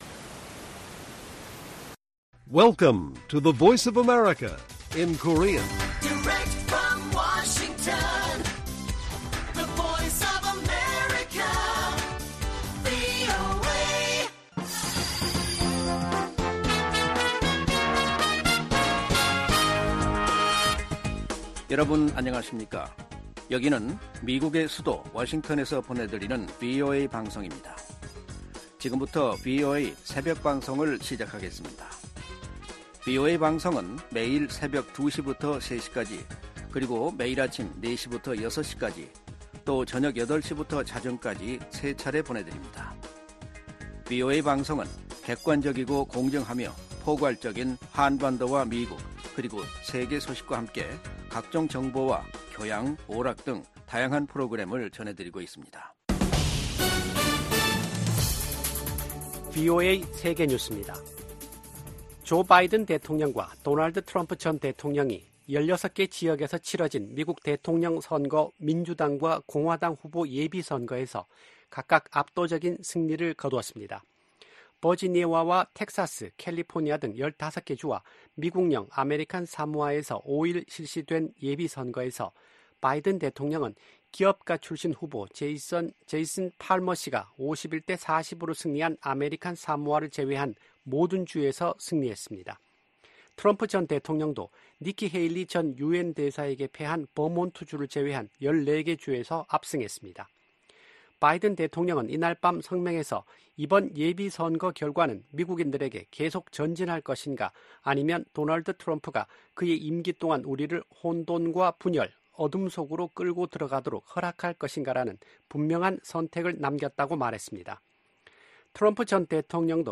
VOA 한국어 '출발 뉴스 쇼', 2024년 3월 7일 방송입니다. 미 국무부 고위 관리가 북한 비핵화에 중간 단계 조치 필요성을 인정했습니다.